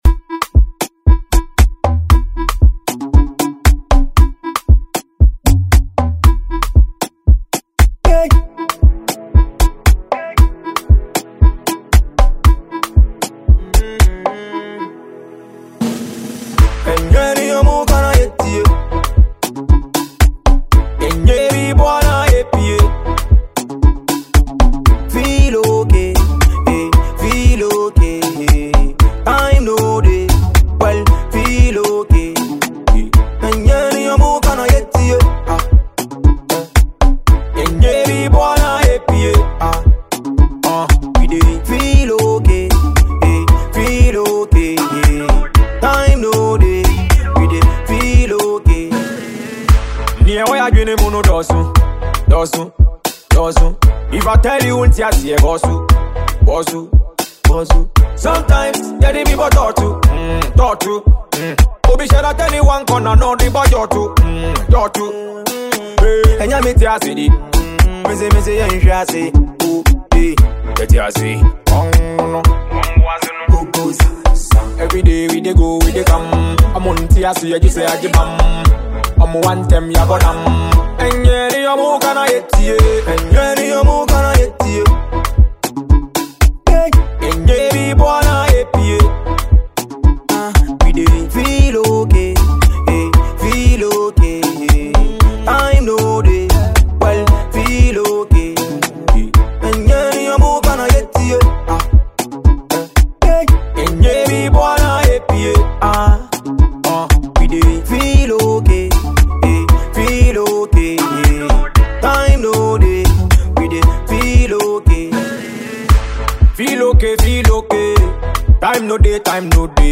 Ghana Music
” emerges as a refreshing and uplifting anthem.